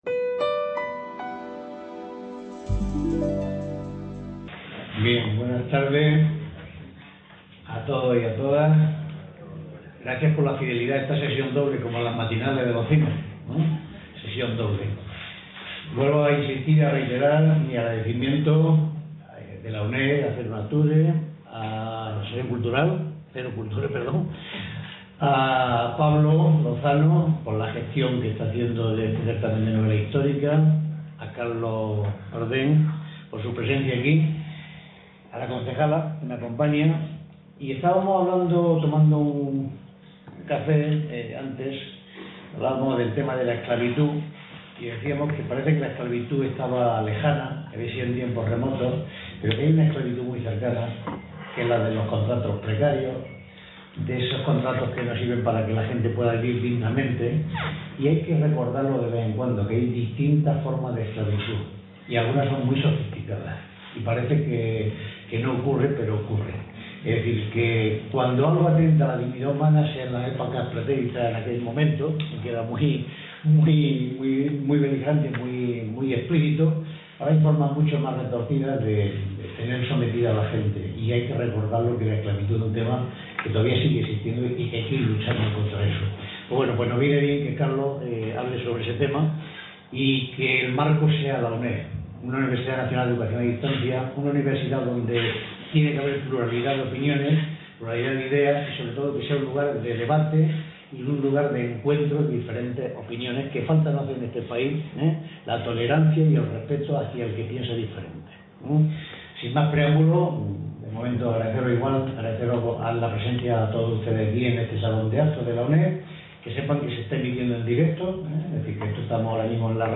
Presentación del libro "MONGO BLANCO" de Carlos Bardem | Repositorio Digital